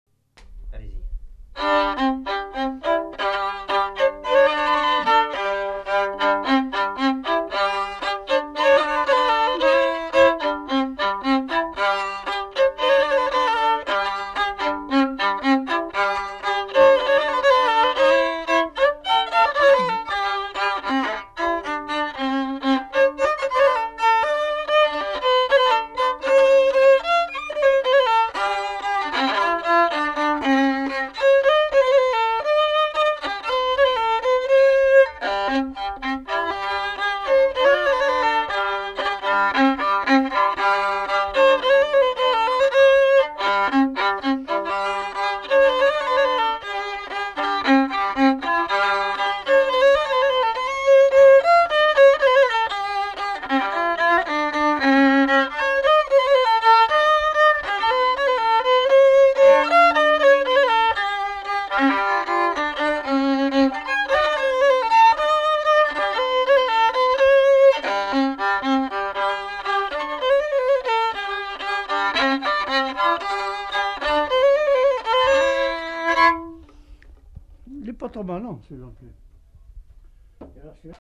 Aire culturelle : Haut-Agenais
Lieu : Castillonnès
Genre : morceau instrumental
Instrument de musique : violon
Danse : rondeau